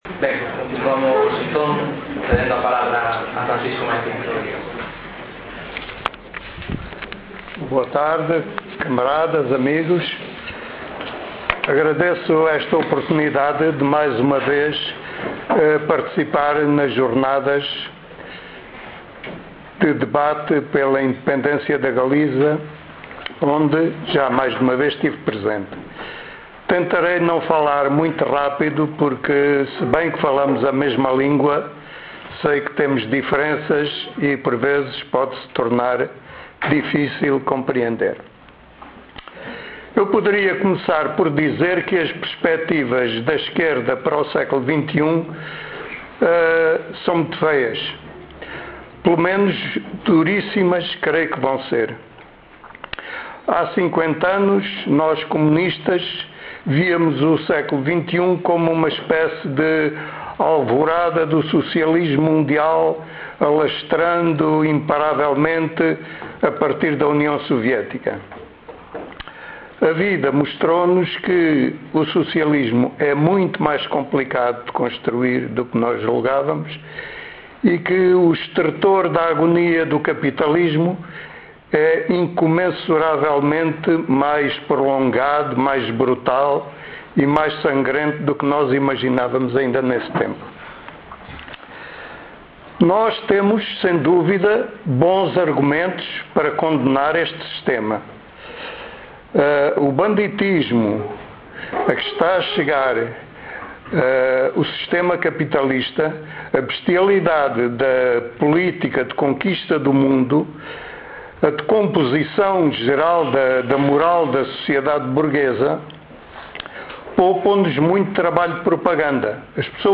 Origem: variante da palestra nas X Jornadas Independentistas Galegas decorridas em Compostela (Galiza) em 2006, organizadas pola organizaçom comunista e independentista galega Primeira Linha.